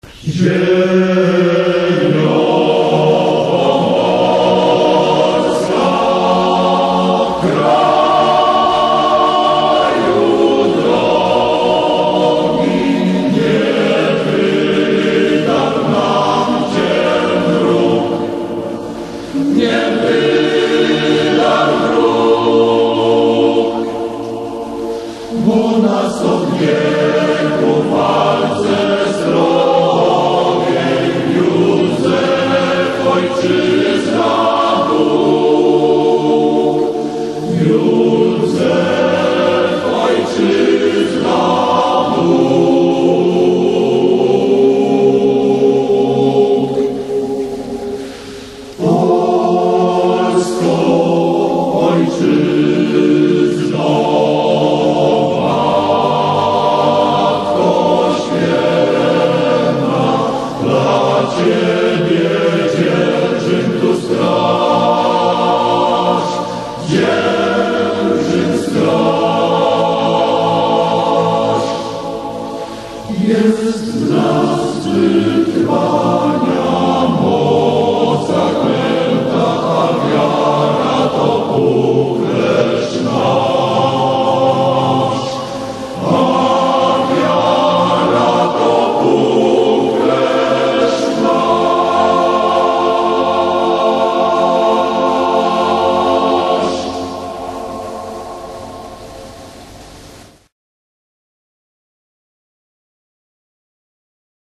Tekst Hymnu Utwór w wykonaniu Chóru „Copernicus” Więcej…